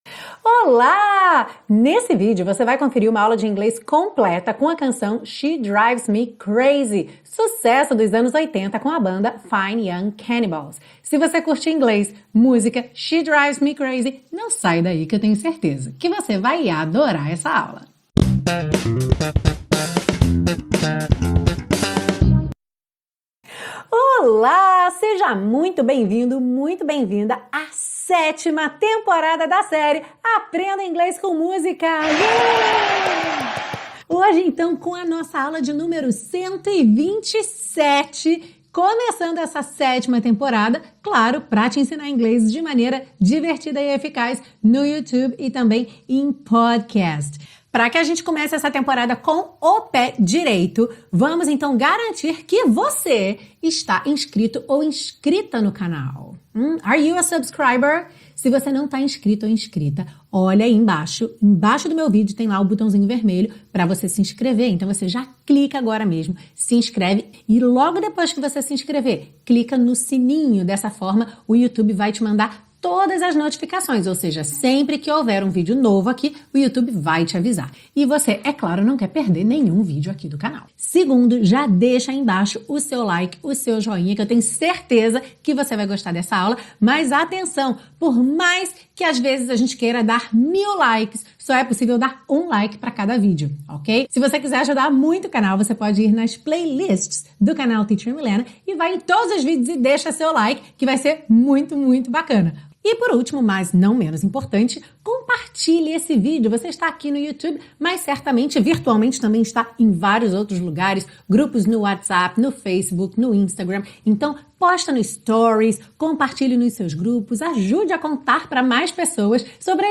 Esta é uma aula de inglês completa, com a compreensão da letra da música, estudo de estruturas do inglês presentes na canção e dicas de pronúncia passo a passo para você aprender a cantar!